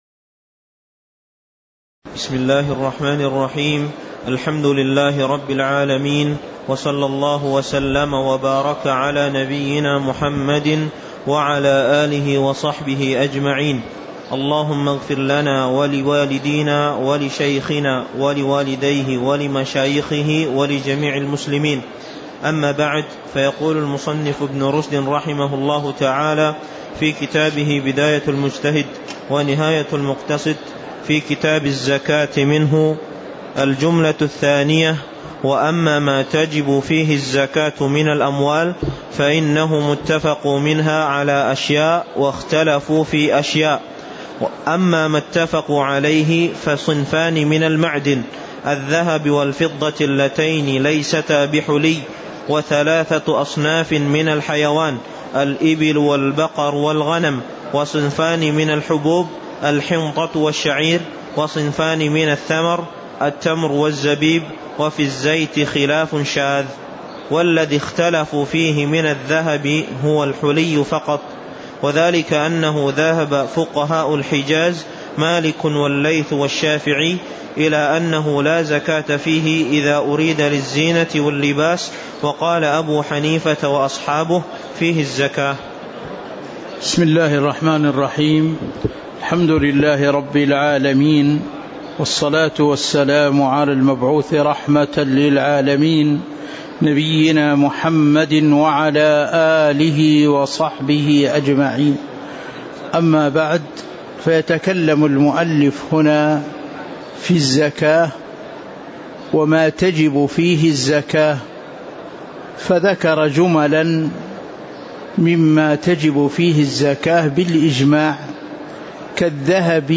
تاريخ النشر ٢٦ جمادى الأولى ١٤٤٥ هـ المكان: المسجد النبوي الشيخ